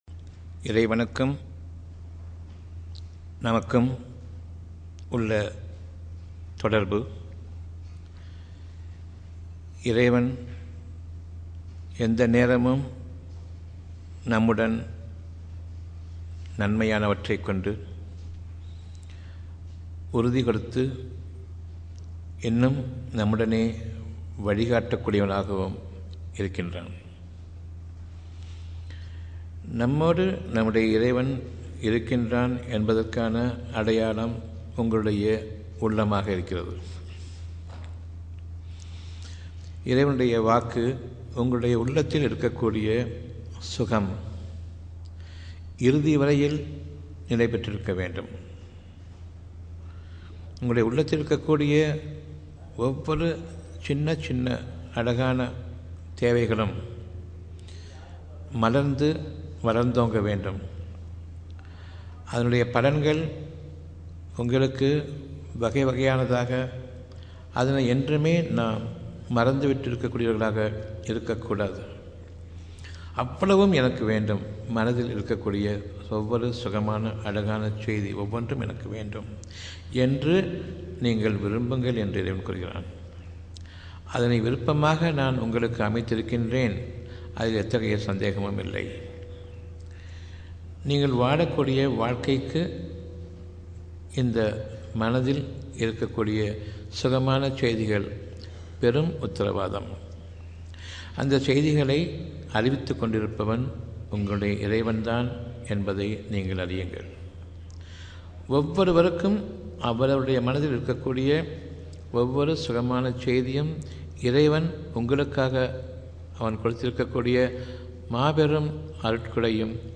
sitra auditorium